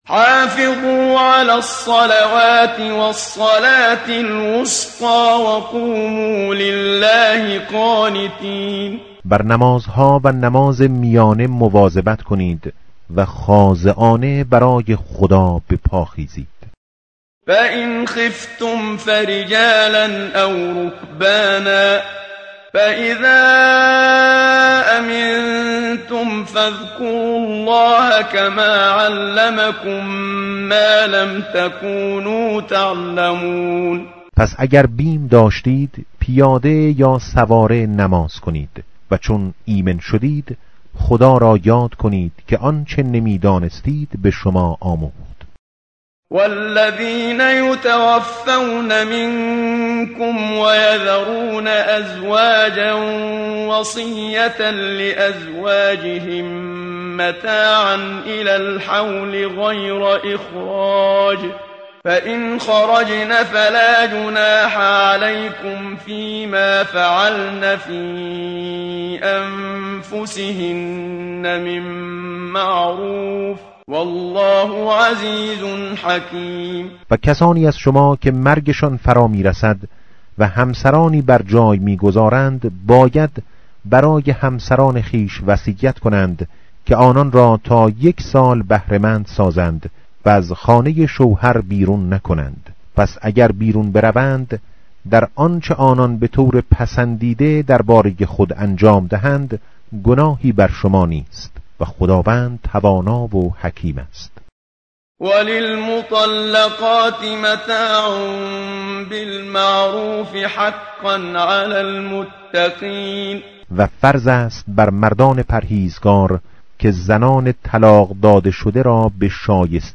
tartil_menshavi va tarjome_Page_039.mp3